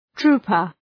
Shkrimi fonetik {‘tru:pər}